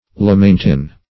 [1913 Webster] The Collaborative International Dictionary of English v.0.48: Lamentin \La*men"tin\, n. See Lamantin .